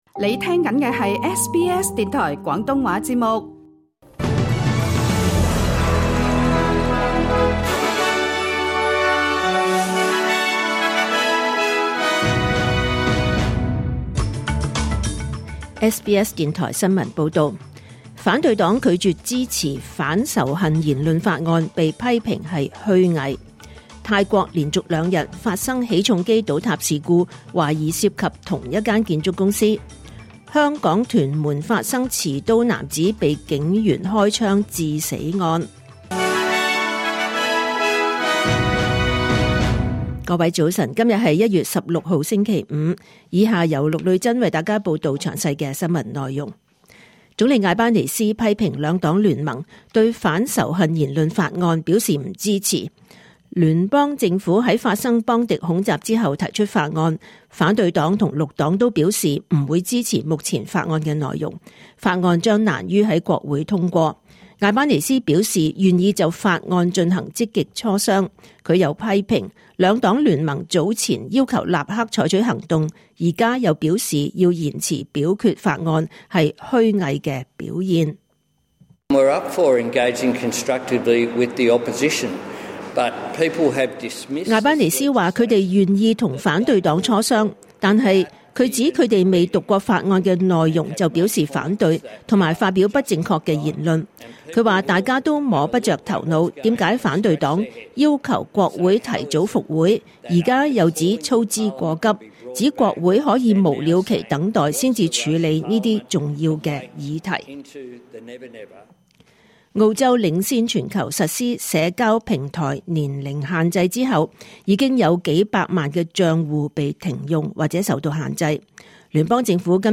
2026年1月16日SBS廣東話節目九點半新聞報道。